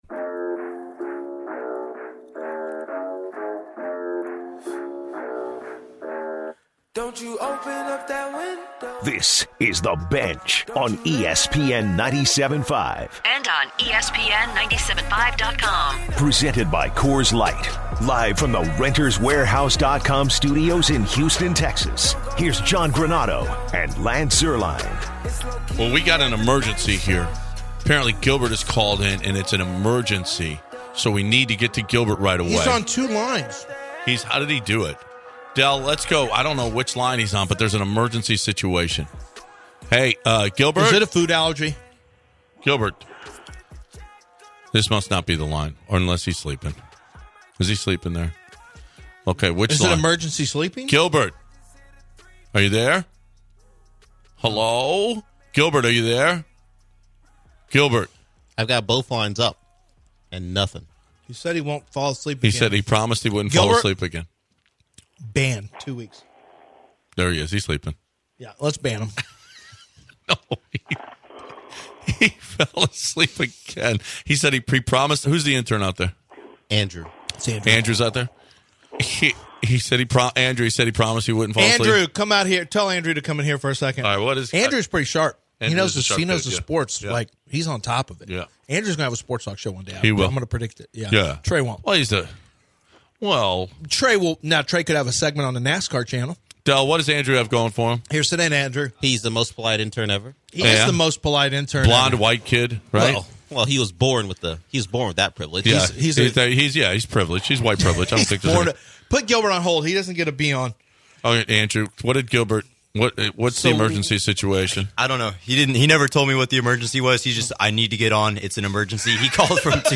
To start the second hour of the show, the guys can’t stop laughing